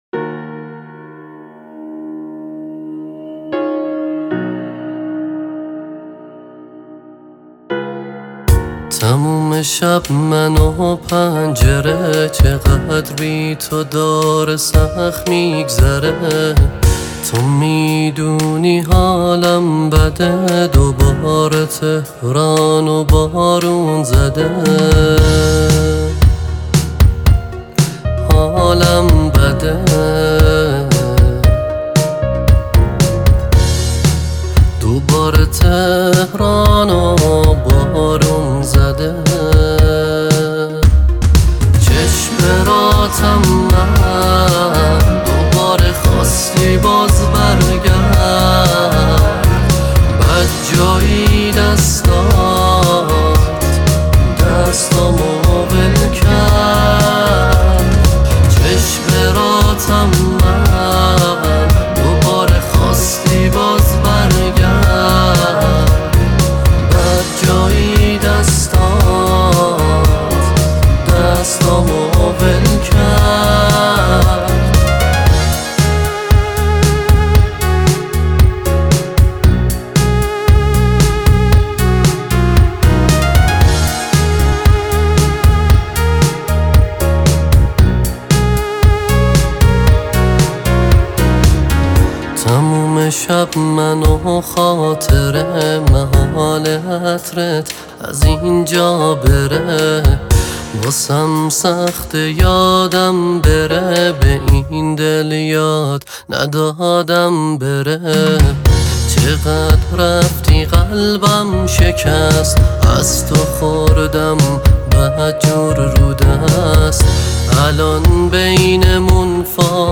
این آهنگ در سبک ژانر اهنگ پاپ خوانده شده است.